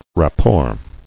[rap·port]